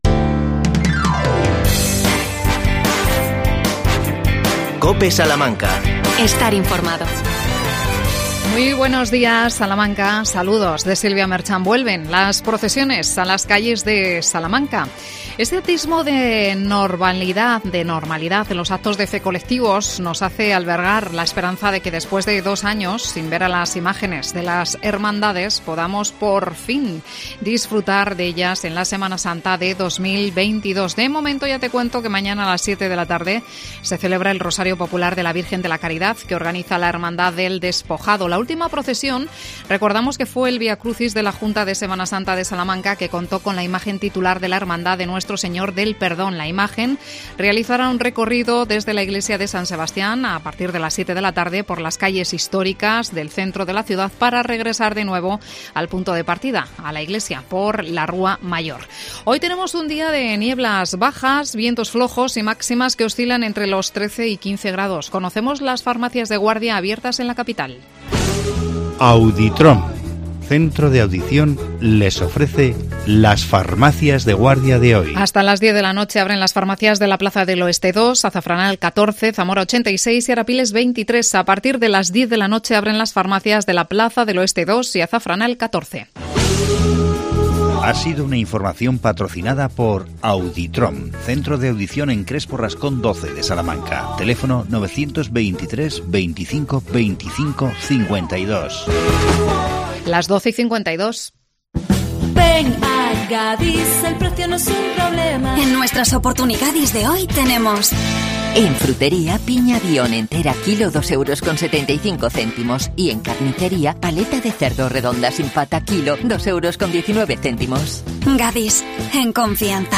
AUDIO: Licitada por el Gobierno la pasarela peatonal que unirá los polígonos de Carbajosa. Entrevistamos a su alcalde Pedro Samuel Martín.